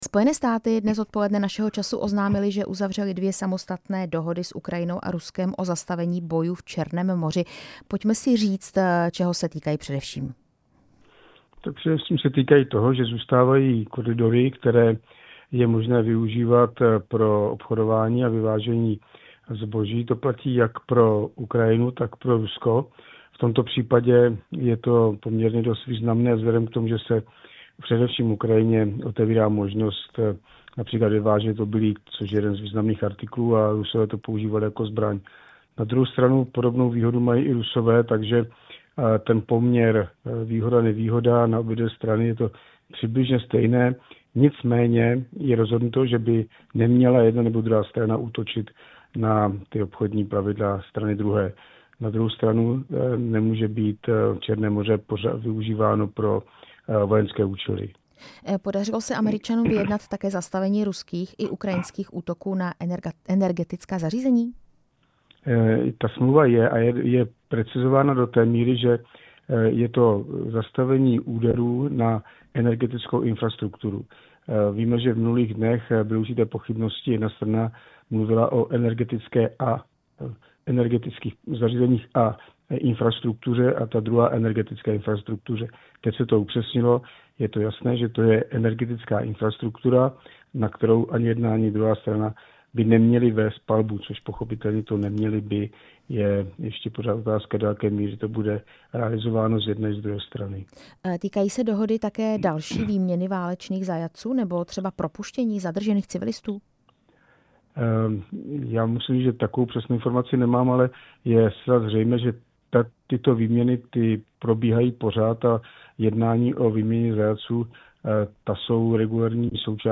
Téma jsme ve vysílání Radia Prostor probrali s bývalým náčelníkem generálního štábu Jiřím Šedivým.
Rozhovor s generálem Jiřím Šedivým